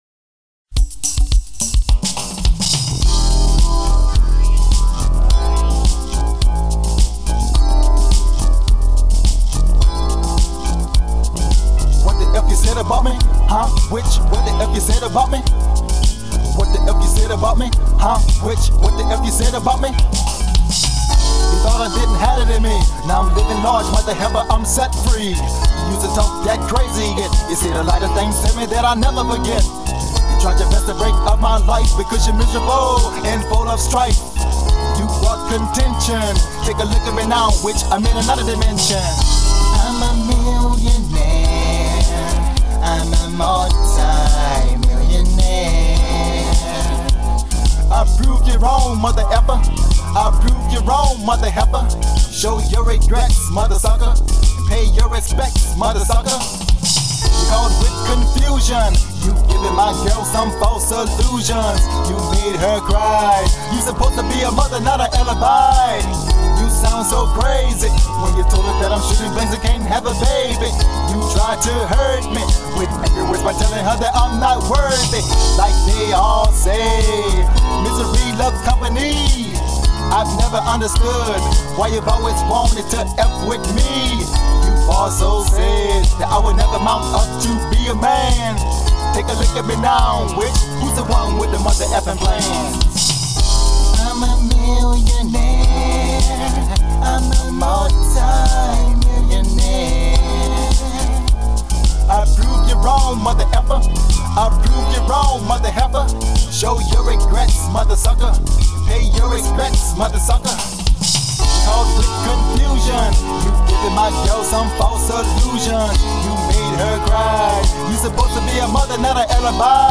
I did all the music lyrics and vocals.